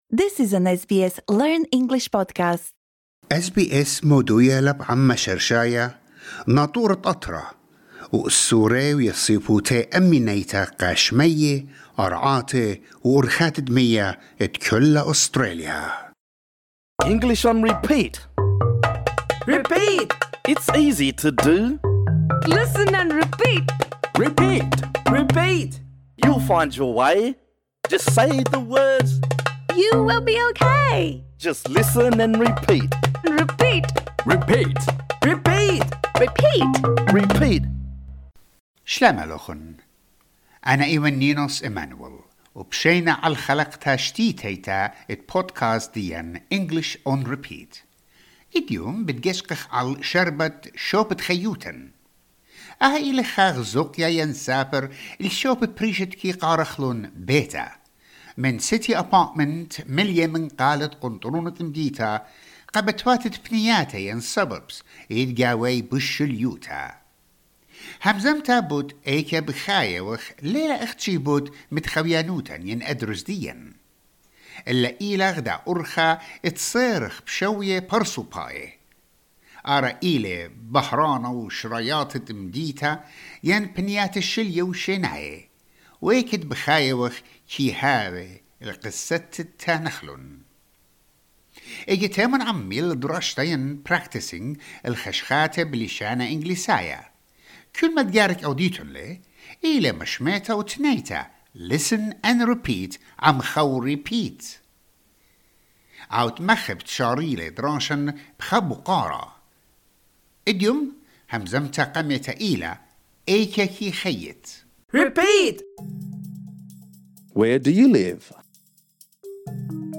This lesson is designed for easy-level learners.